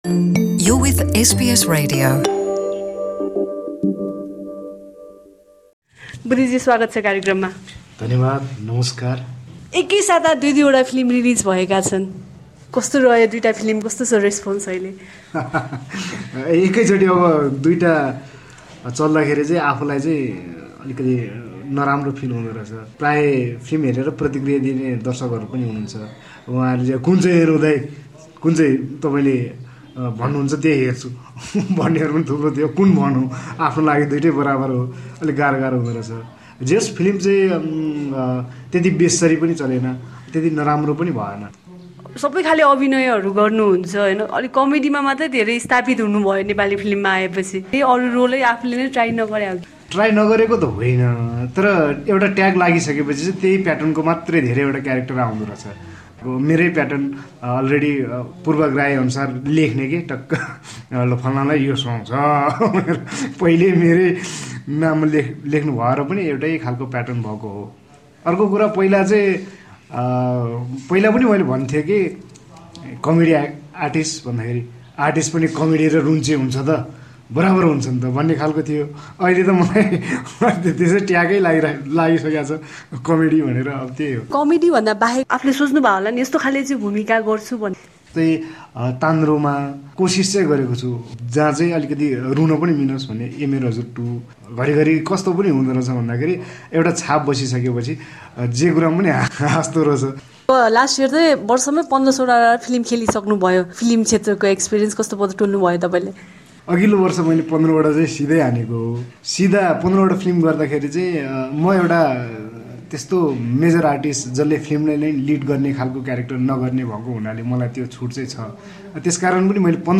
Tamang, who entered Nepal's arts and entertainment industry through theater has now become one of the most recognized faces in the film and television industry. Two of his films, Changa and Mattimala were released within one week recently. Buddhi Tamang spoke to SBS Nepali about his journey so far.